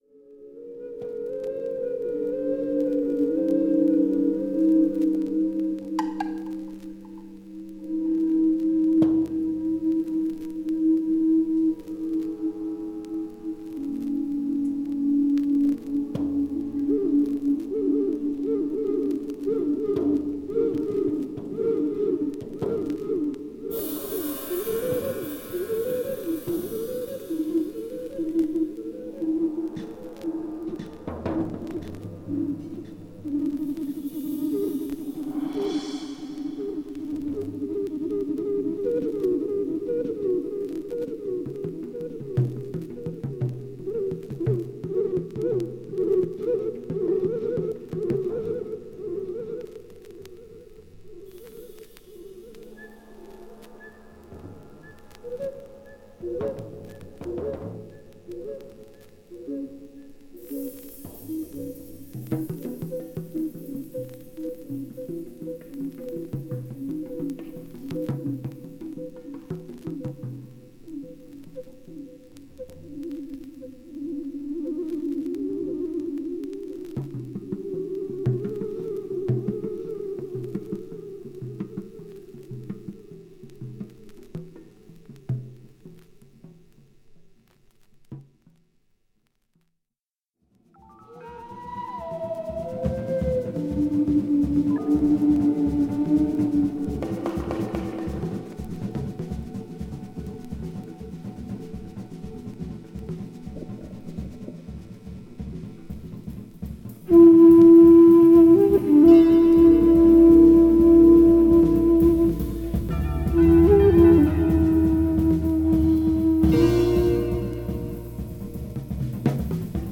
JAZZ
ドラマー